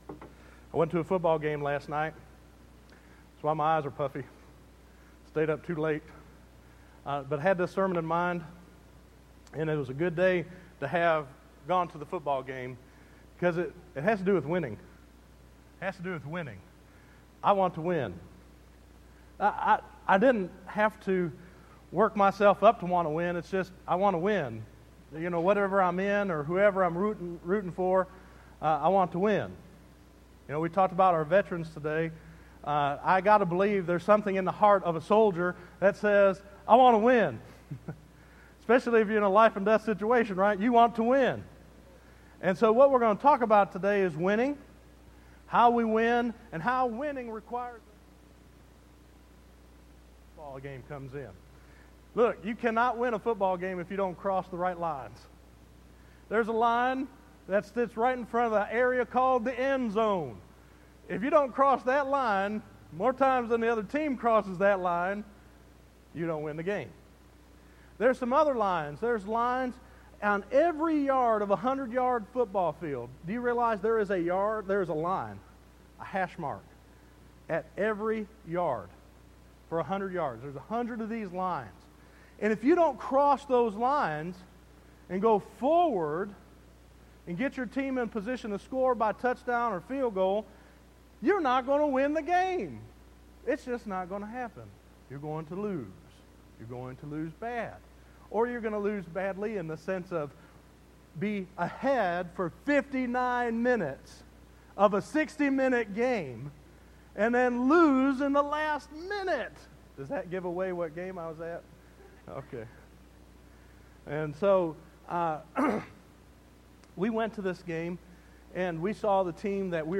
Listen to Crossing Lines to Win 1 Corinthians 9 vs 18 to 23 - 11_11_12_Sermon.mp3